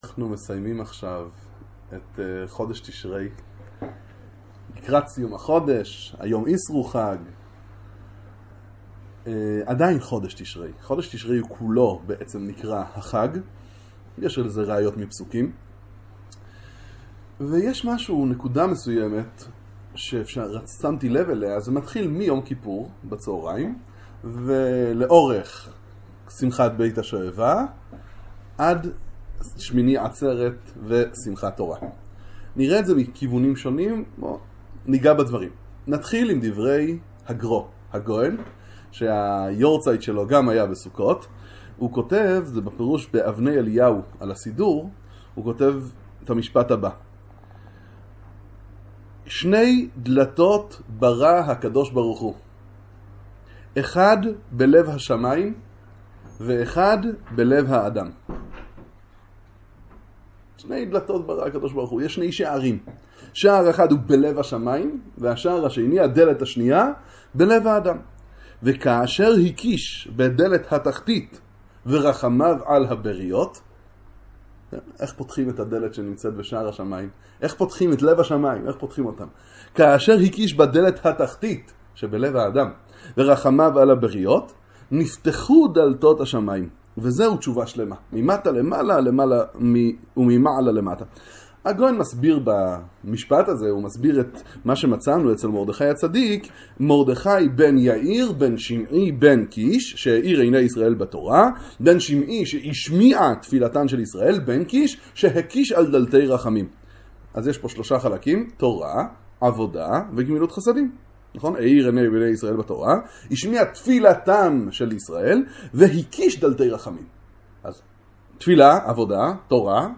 שיעור בספרי איזביצא